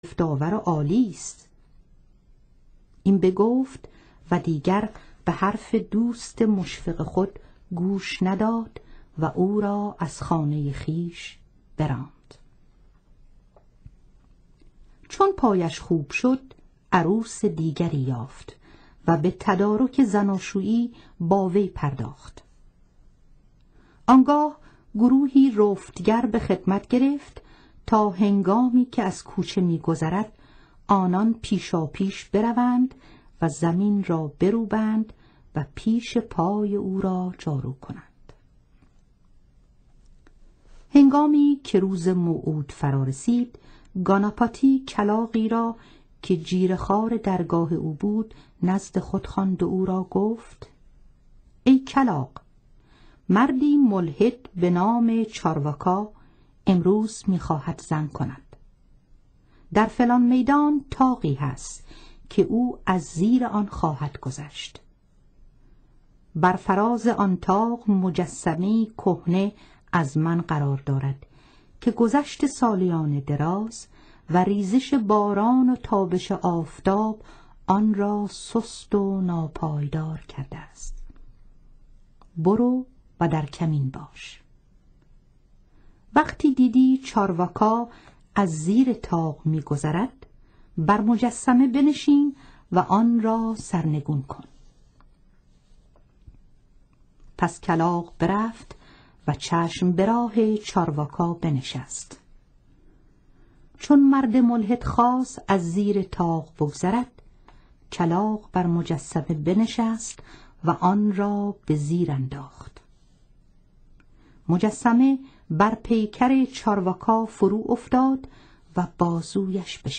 کتاب صوتی مهپاره